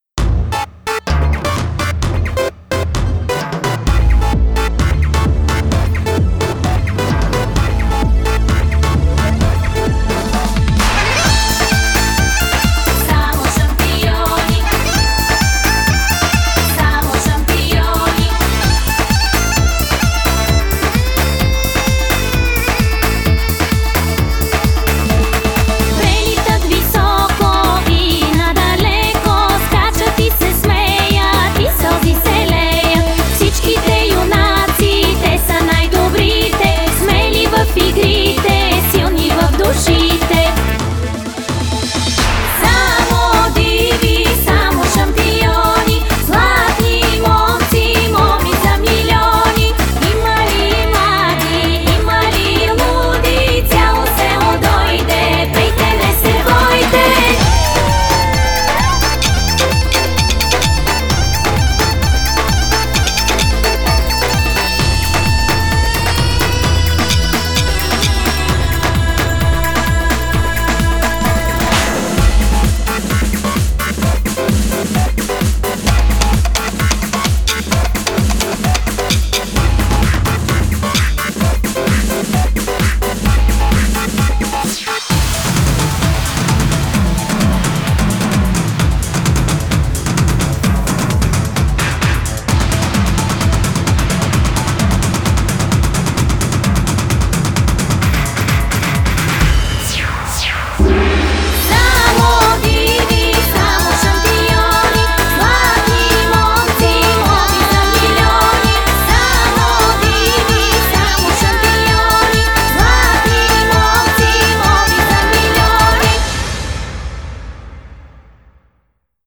BPM130
Audio QualityPerfect (High Quality)
Genre is [BULGARIAN RHYTHM #2]